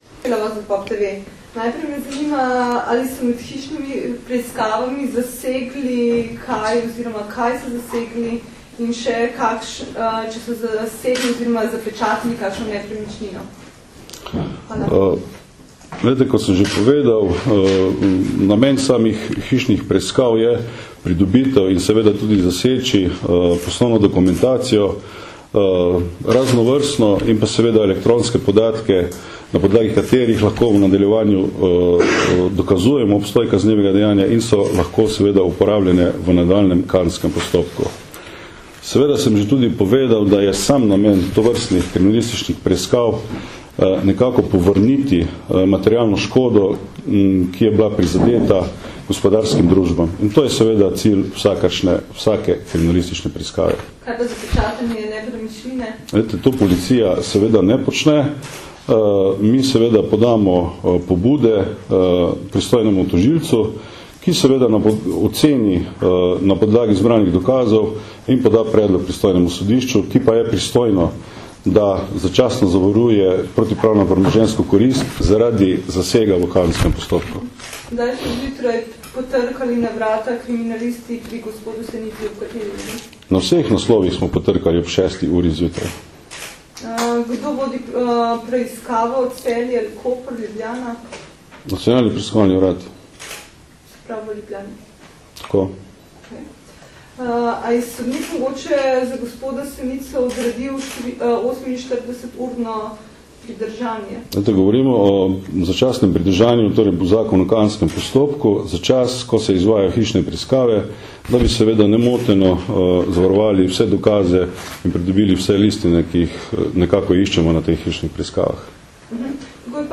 Novinarska vprašanja in odgovori nanje (mp3)